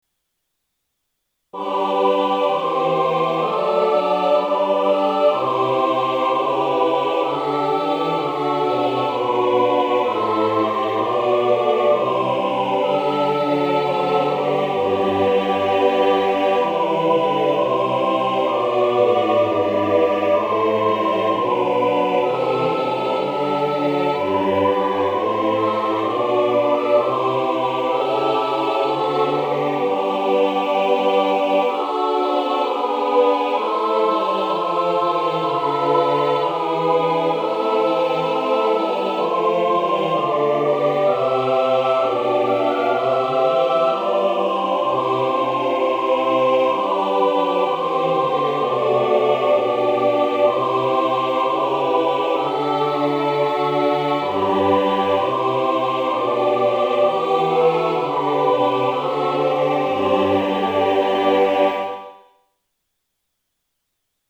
Sample Sound for Practice 練習用参考音源：MIDI⇒MP3　Version A.17
Tonality：G (♯)　Tempo：Quarter note = 64
1　 Chorus